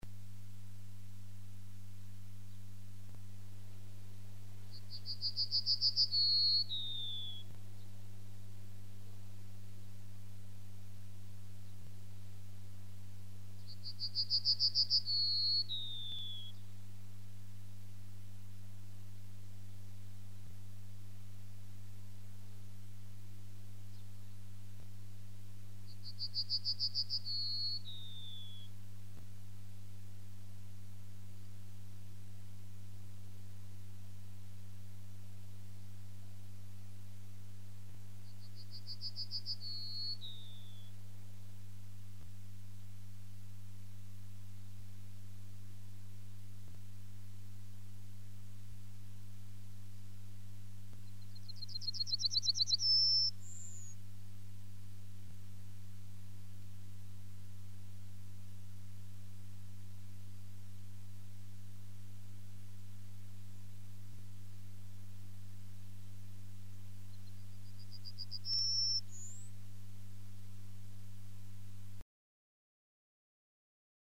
Strnad_obecny.mp3